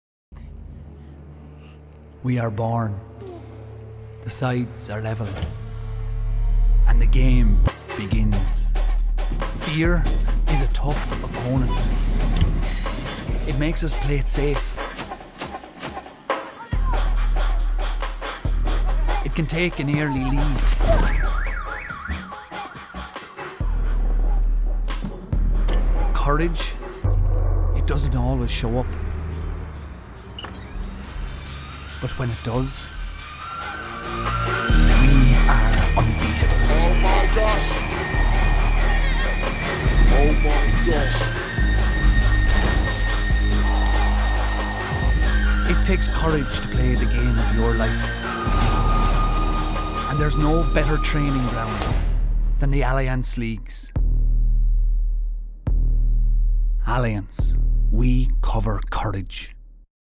Male
30s/40s, 40s/50s
Irish South East